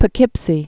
(pə-kĭpsē, pō-)